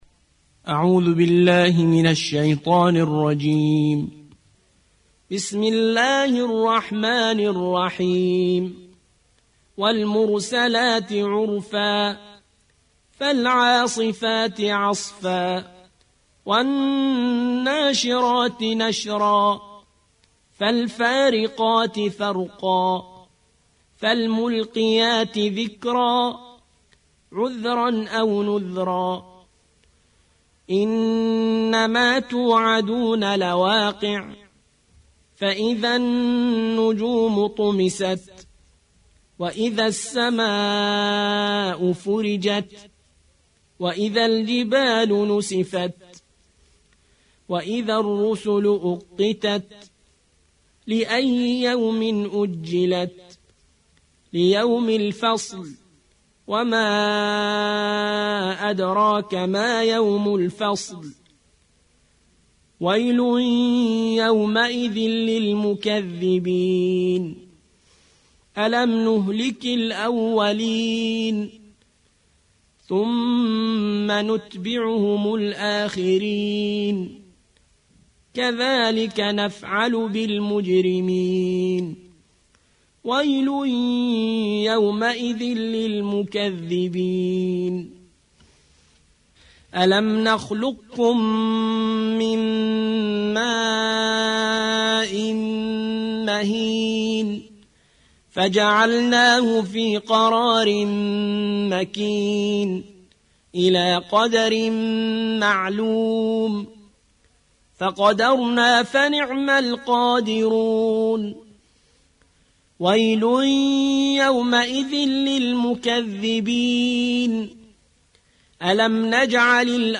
77. سورة المرسلات / القارئ